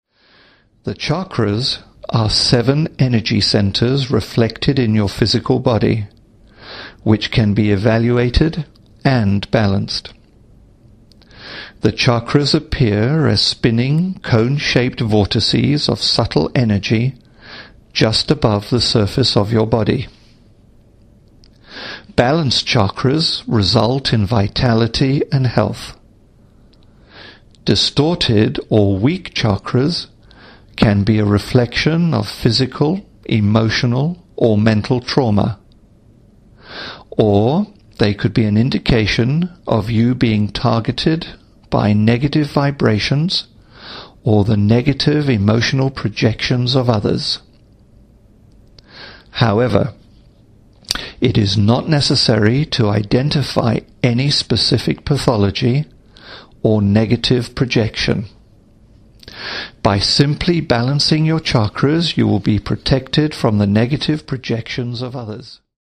These Hypnosis Programs use only voice (no ambience or subliminal elements) to enable you to access and use the power of your subconscious mind.
Play Audio Sample H3019 - Chakra Balancing - Voice Only Hypnosis **
Our Voice Only Hypnosis Programs contain only voice, and you will be guided through the session.
These programs do not contain any ambience or subliminal message elements.